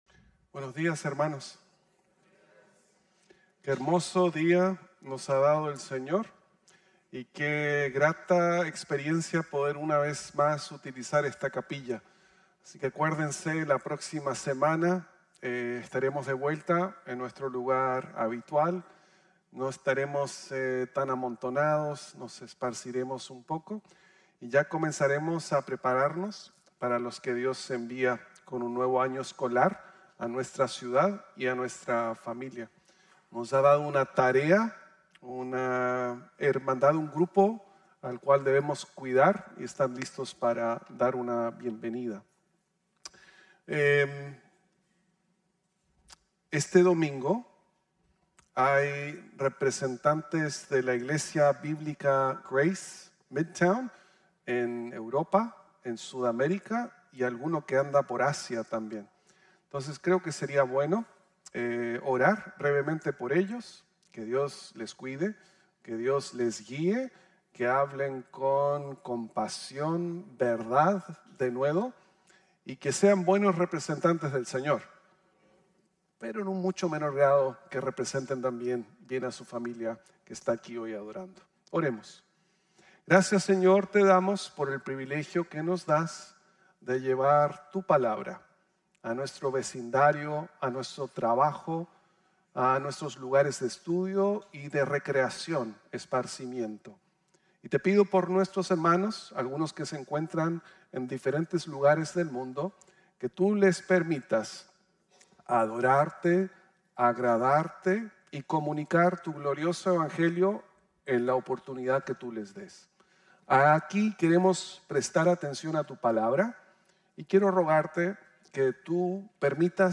Jonás y el Pez Obediente | Sermon | Grace Bible Church